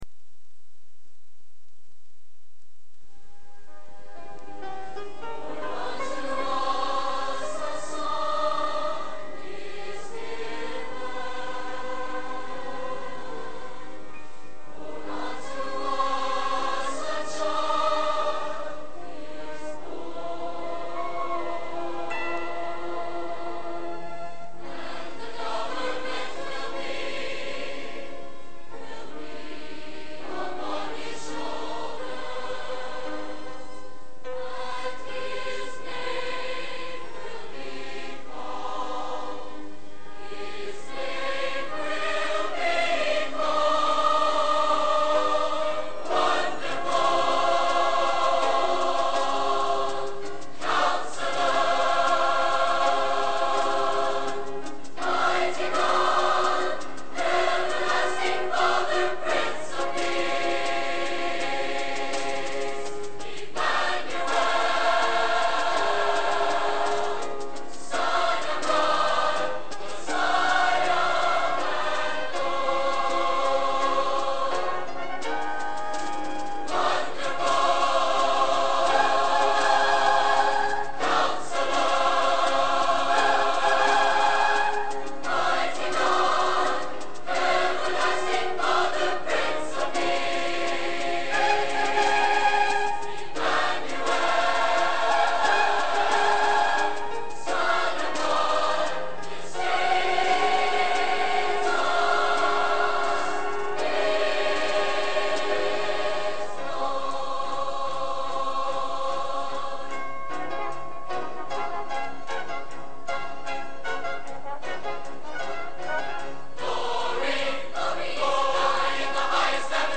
Genre: Cantata / Sacred | Type: Christmas Show |